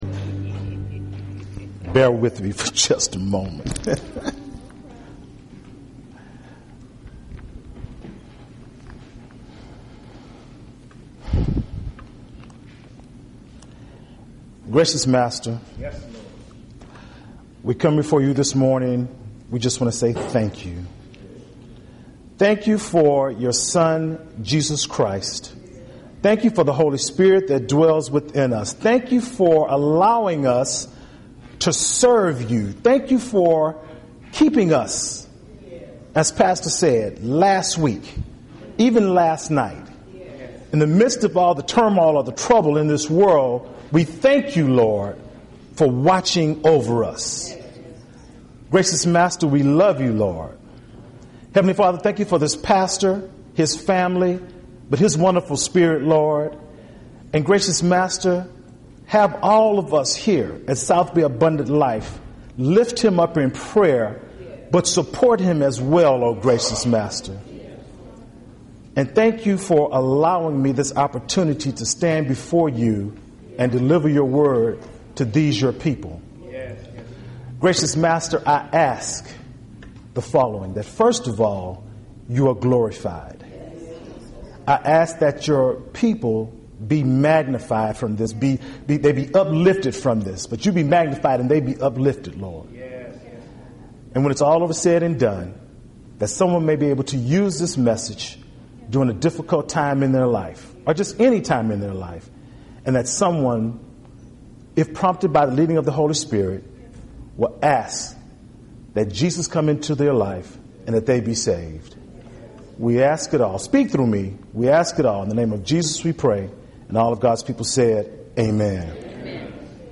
2016 Audio Sermons Audio Sermon Save Audio https